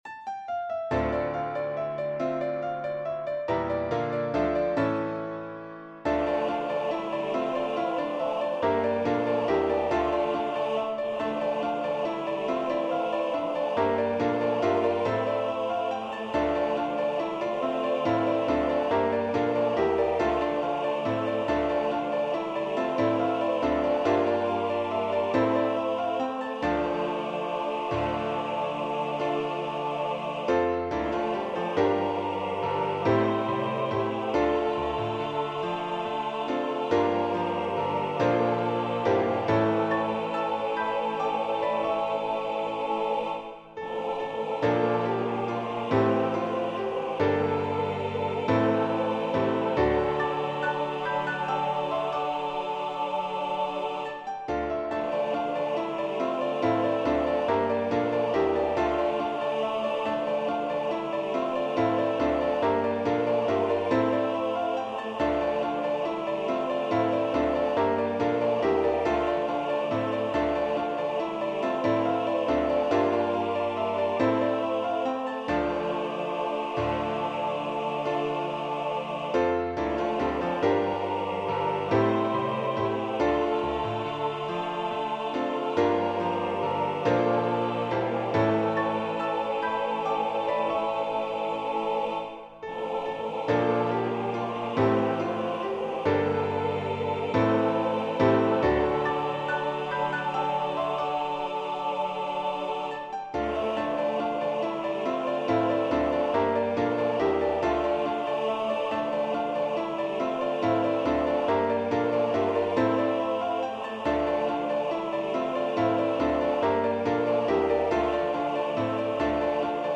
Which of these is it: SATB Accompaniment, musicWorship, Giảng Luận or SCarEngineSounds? SATB Accompaniment